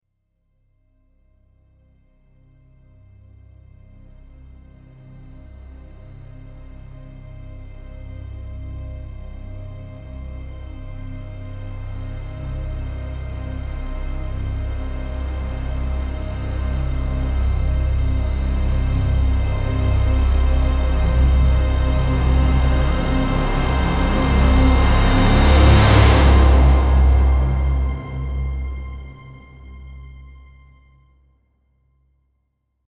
Haunted Orchestral Rising Tension Sound Effect
Chilling orchestral sound effect with swelling strings and dark atmospheric layers. Builds suspense and fear, perfect for horror scenes, haunted houses, trailers, and Halloween media.
Haunted-orchestral-rising-tension-sound-effect.mp3